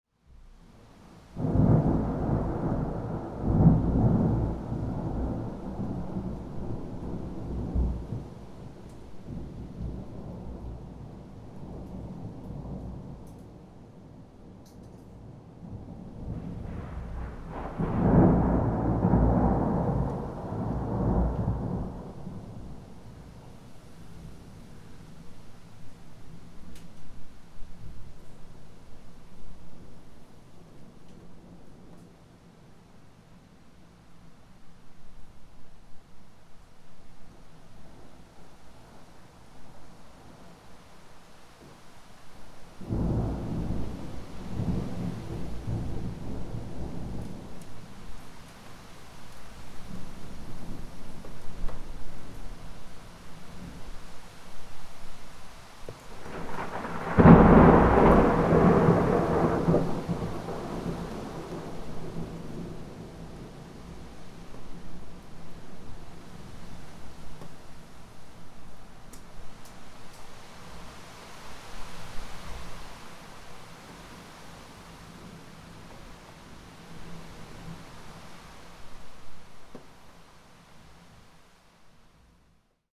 Thunderstorm Approaching Sound Effect
Powerful and intense sound of thunderstorm approaching. The wind is blowing stronger and stronger. Deep thunder rolls of a summer or spring storm drawing near.
Thunderstorm-approaching-sound-effect.mp3